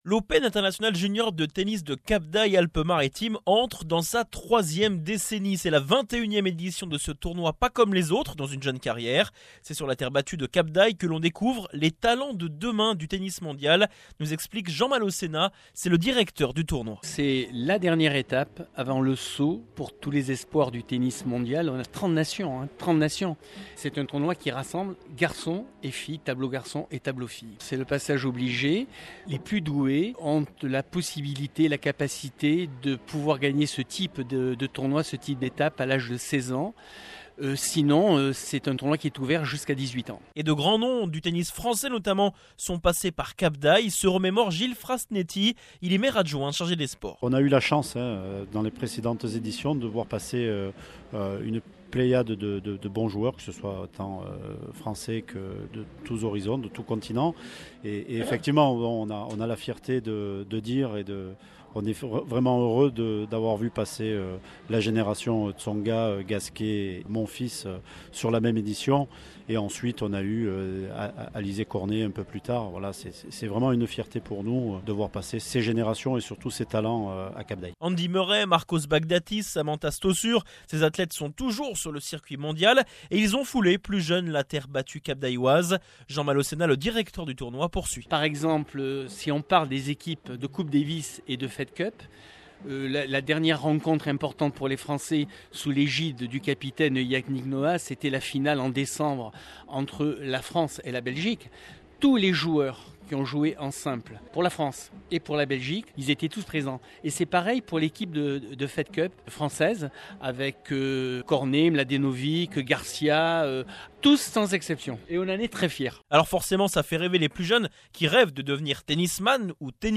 Reportage radio sur France Bleu Azur - Tennis Cap d'Ail
reportage-france-bleu.mp3